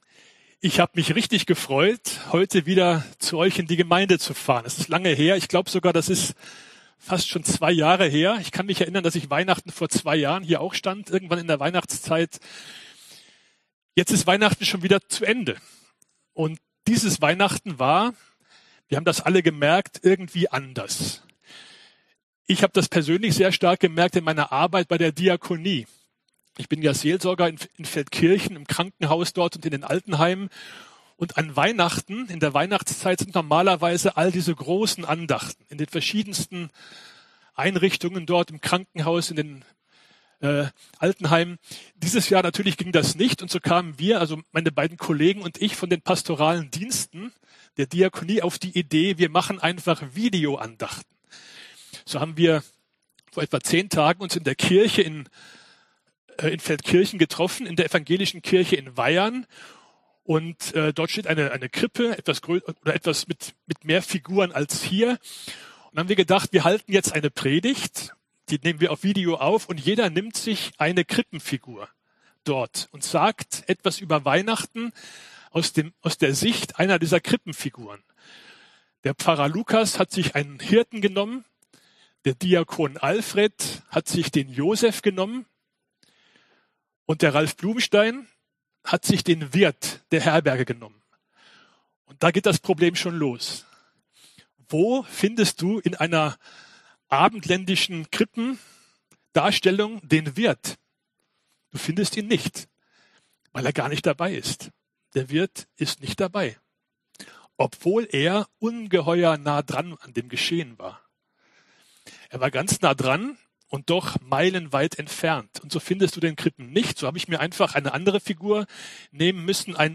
Aktuelle Predigtreihe – FEG Klagenfurt